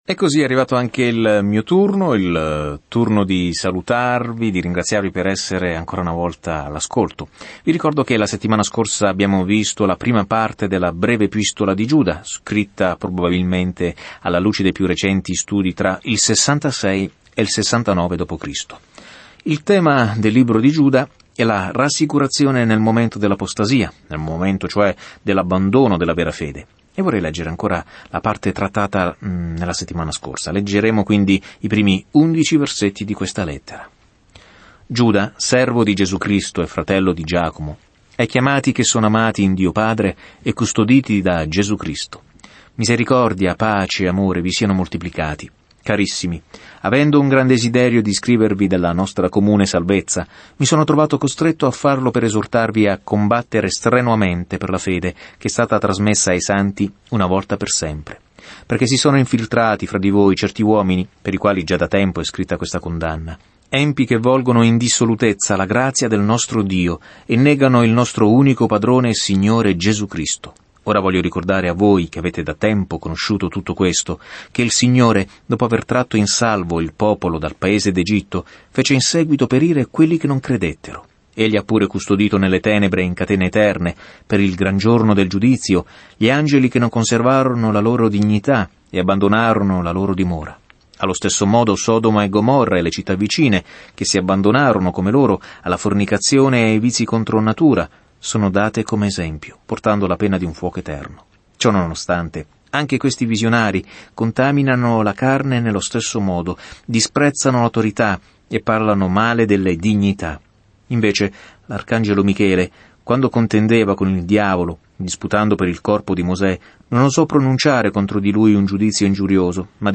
Scrittura Lettera di Giuda 1:12-16 Giorno 5 Inizia questo Piano Giorno 7 Riguardo questo Piano “Lotta per la fede” dice questa breve ma diretta lettera di Giuda ai cristiani che lottano contro i falsi maestri che si sono insinuati nella chiesa inosservati. Viaggia ogni giorno attraverso Giuda mentre ascolti lo studio audio e leggi versetti selezionati della parola di Dio.